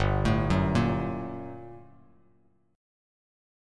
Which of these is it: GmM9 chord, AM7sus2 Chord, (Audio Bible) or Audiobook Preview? GmM9 chord